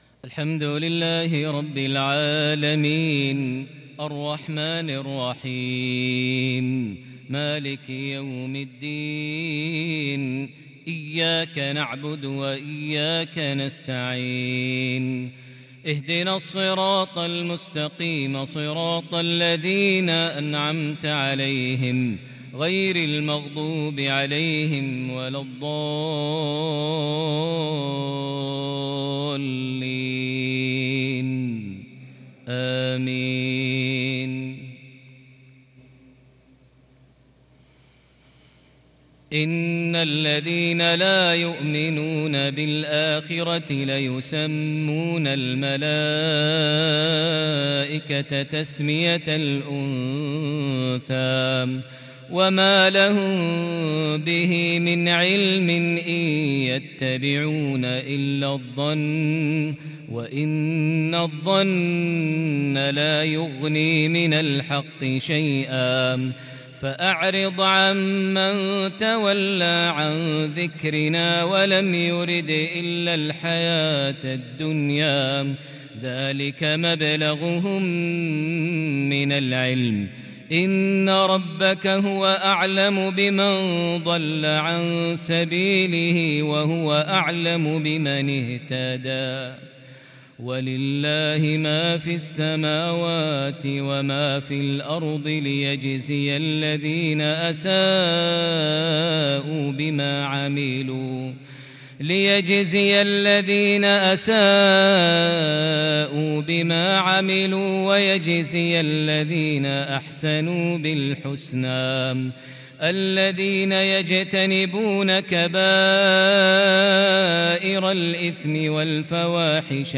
عشائية متألقة بتفرد وتحبير الكرد من سورة النجم (27-53) | الجمعة 16 شوال 1442هـ > 1442 هـ > الفروض - تلاوات ماهر المعيقلي